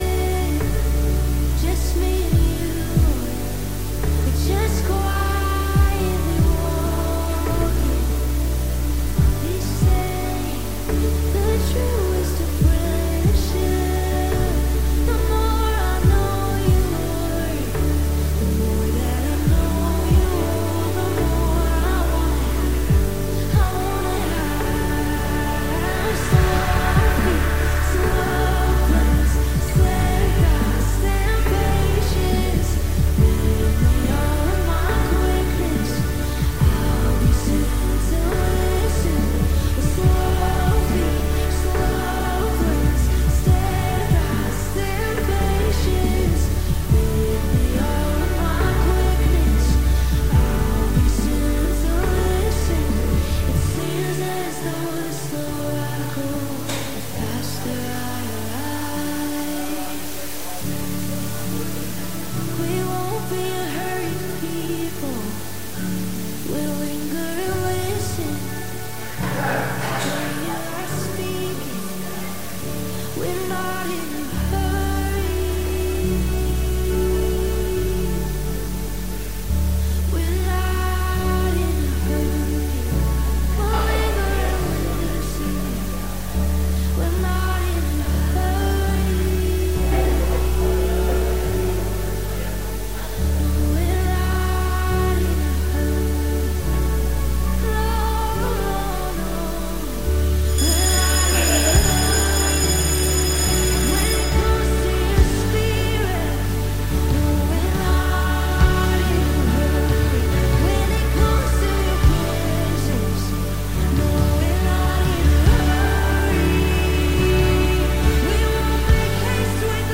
Teaching on Bridging the Gap between Generations and what they need in their spiritual walk with The Lord.